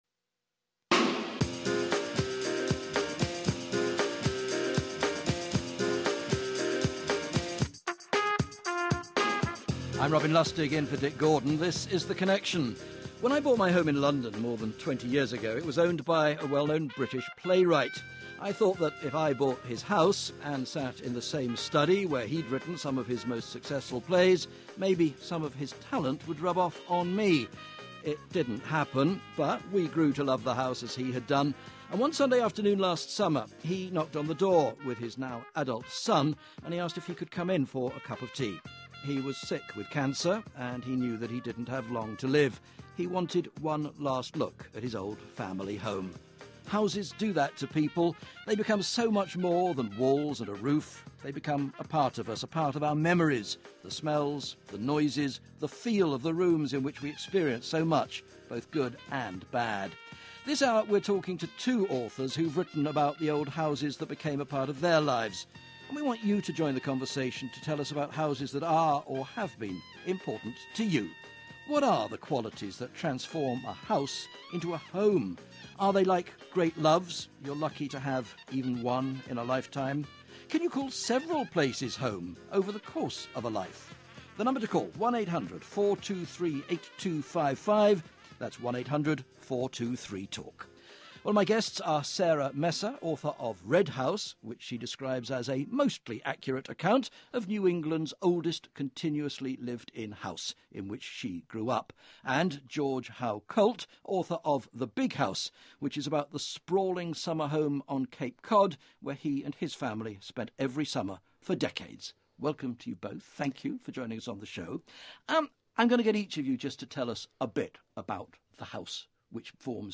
It takes a particular blend of vision and respect to negotiate the fine line between preserving what makes the house special and needlessly living in the past. Two authors who have written about their families’ old houses join us to discuss the particular sense and sensibility of that place we call home.